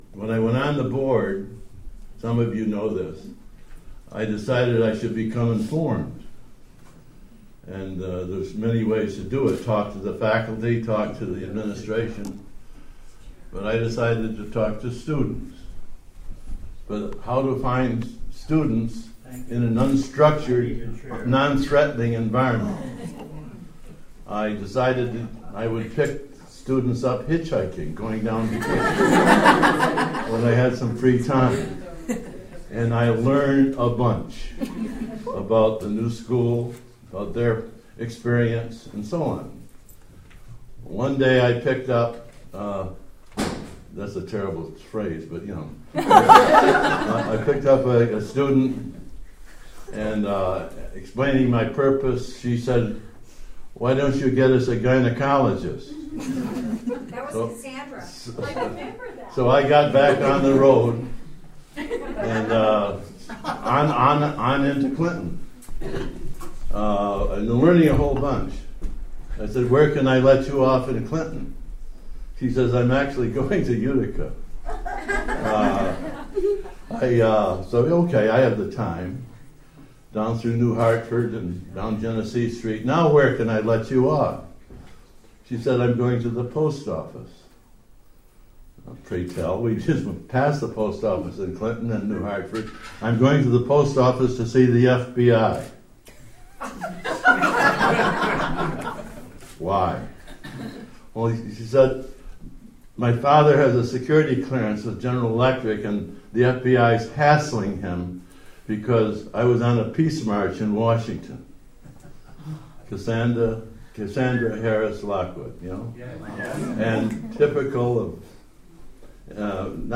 A crowd of alumnae, current students, past faculty and well-wishers gathered on April 16, 2010 to dedicate the marker, and gathered afterward in the nearby Molly Root House for champagne and Open Mike reception.
Marker dedication attendees waited in the rain